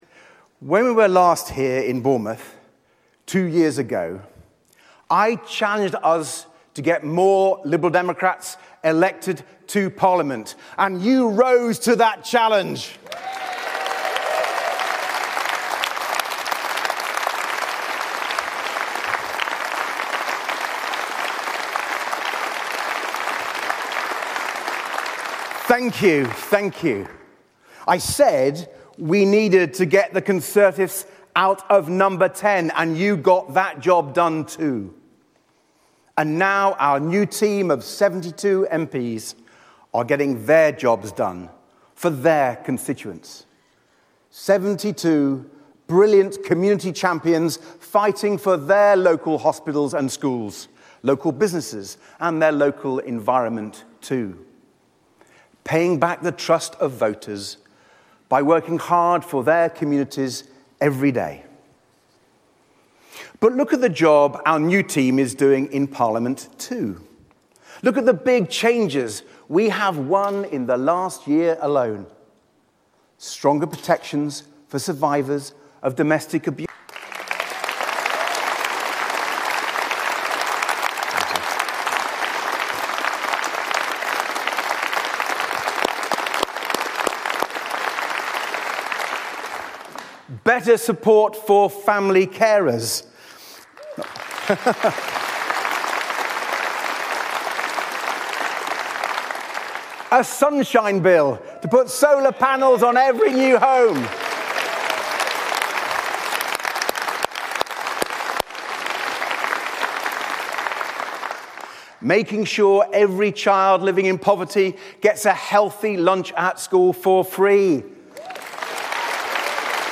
Lib Dem leader Sir Ed Davey addresses his party conference.